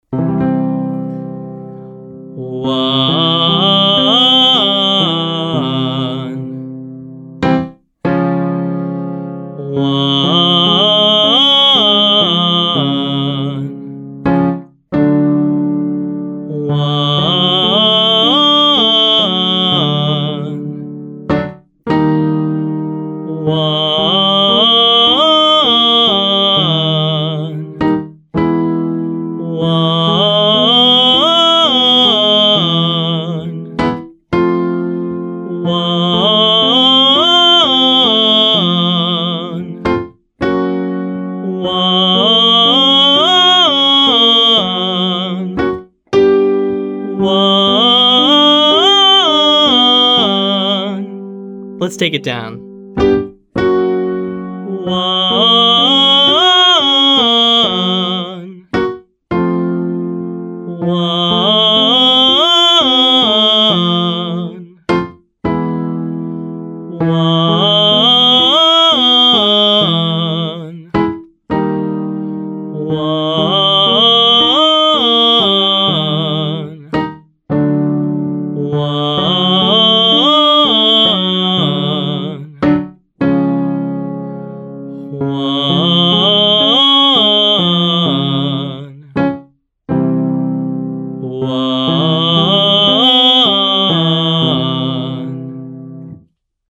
Belting
Part 3: belting.